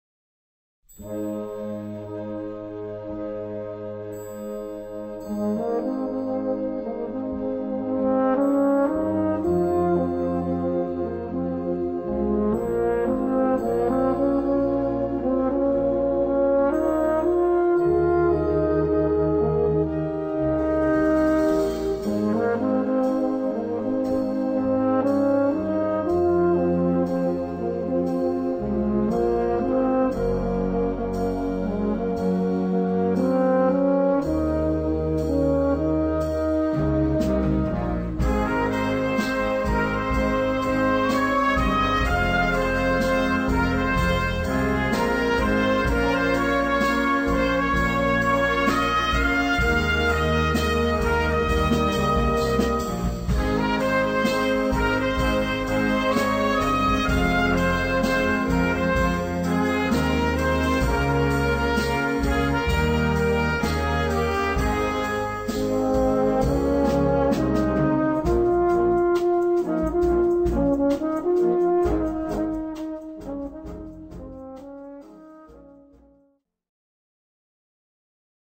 Gattung: Solo für Bariton oder Euphonium
Besetzung: Blasorchester